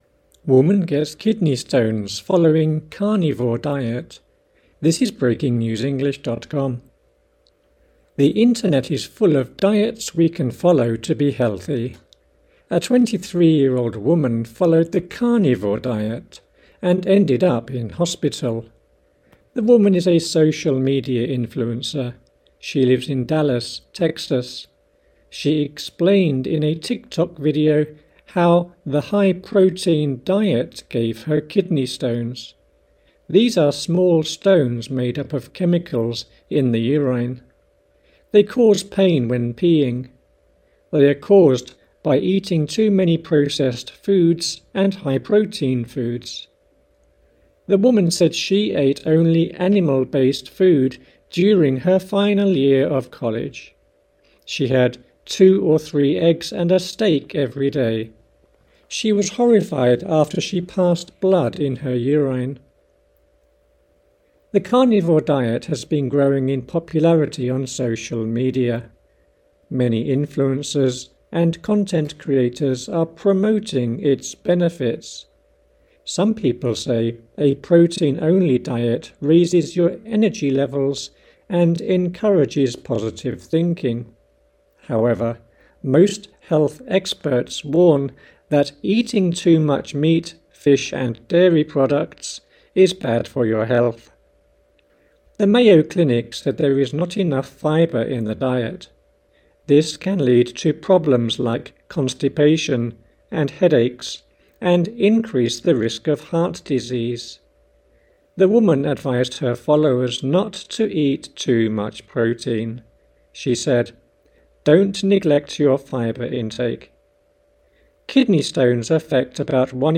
AUDION(Slow)